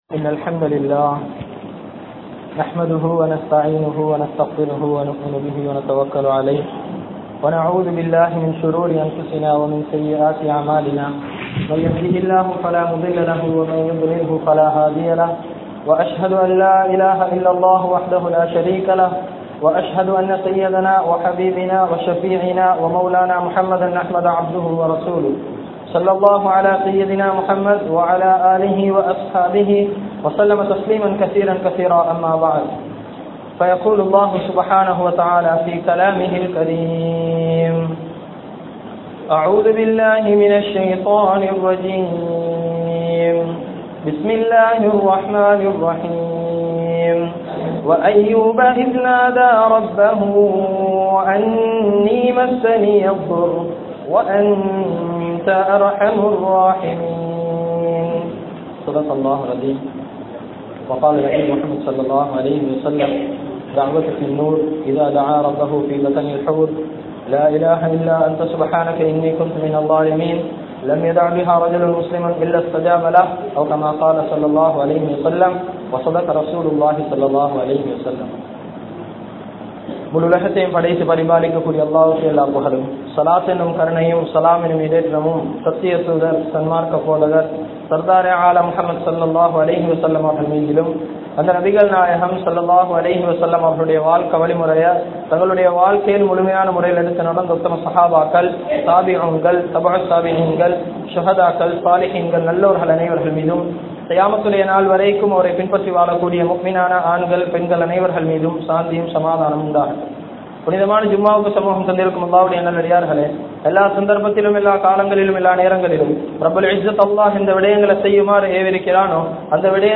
Ayyoob(Alai)Avarhalin Valkai Mun Maathiri (ஐயூப்(அலை)அவர்களின் வாழ்க்கை முன்மாதிரி) | Audio Bayans | All Ceylon Muslim Youth Community | Addalaichenai